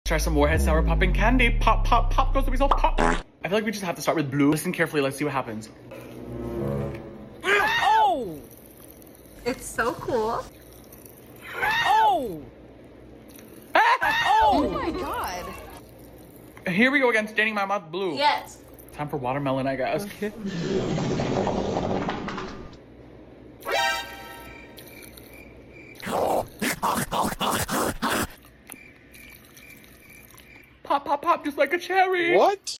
Warhead Sour Popping Candy POP Sound Effects Free Download